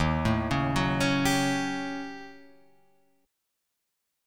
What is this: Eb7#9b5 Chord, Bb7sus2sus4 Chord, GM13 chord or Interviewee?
Eb7#9b5 Chord